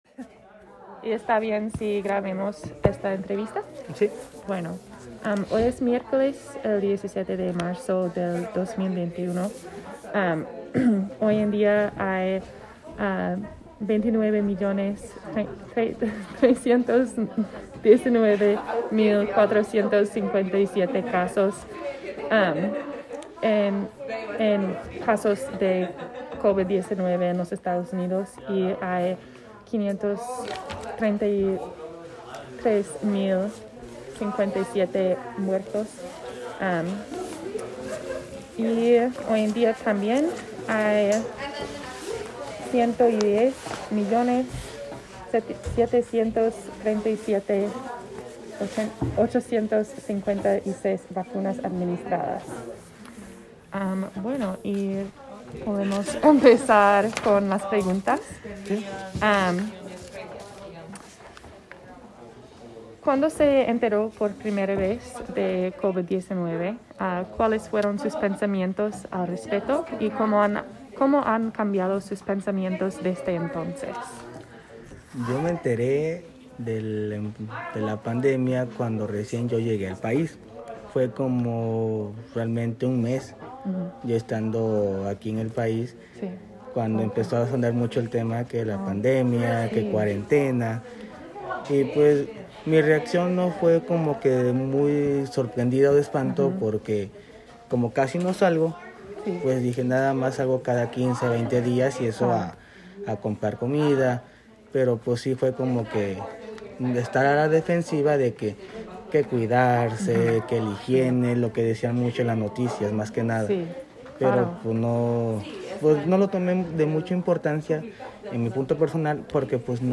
Oral history, Anonymous farmworker.
Audio interview file, anonymous, farmworker, March 17, 2021, 1:03 PM// Archivo de entrevista de audio Anónimo, trabajador agrícola, 17 de marzo de 2021, 1:03 PM